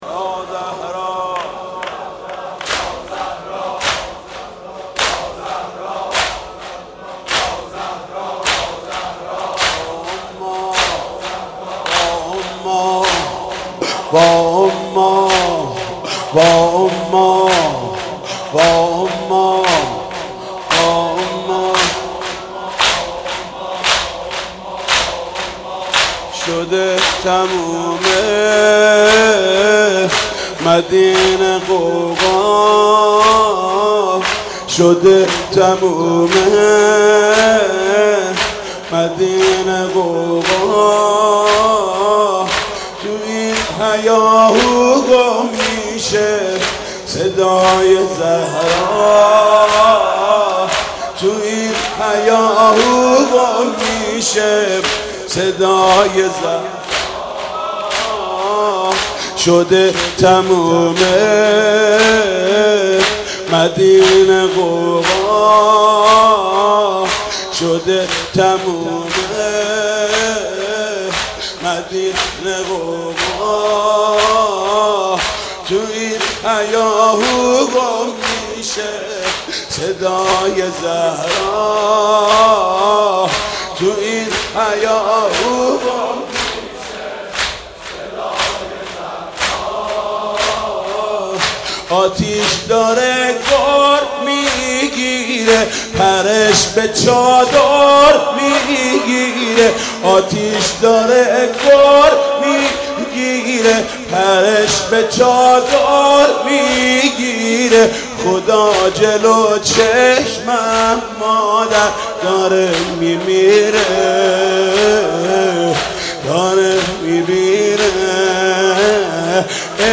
زمینه سوزناک